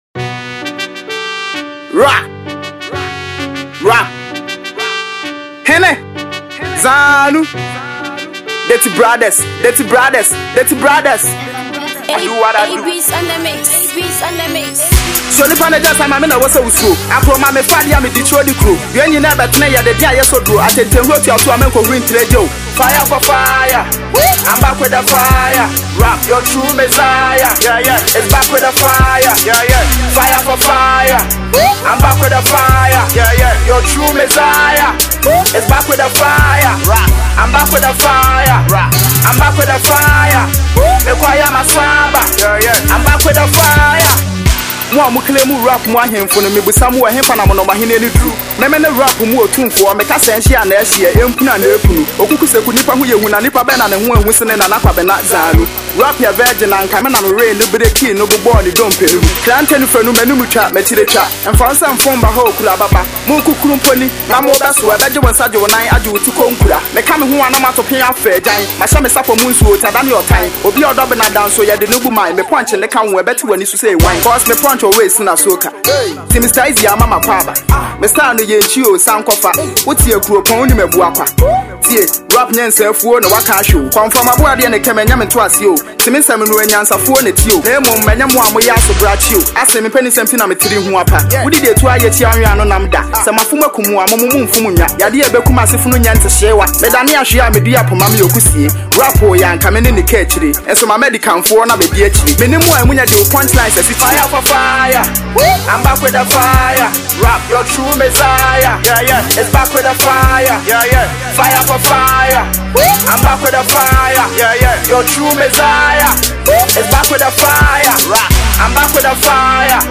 dope tune